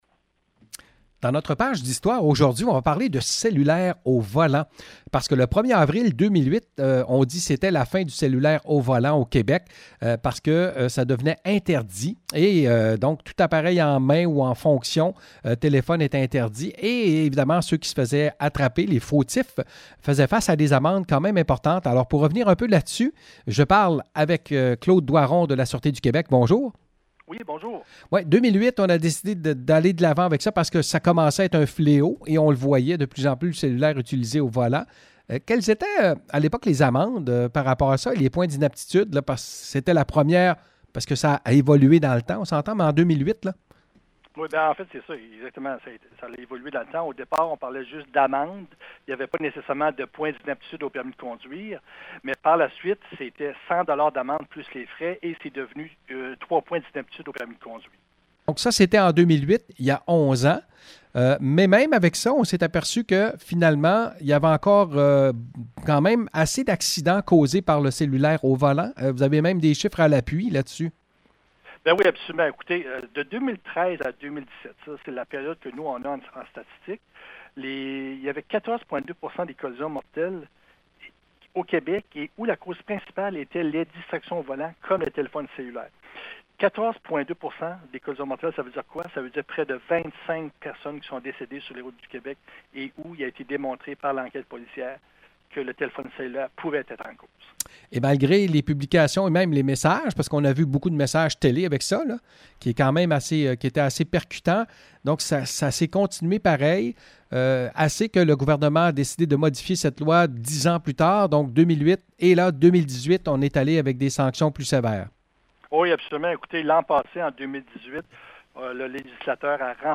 Rétrospective pour avril.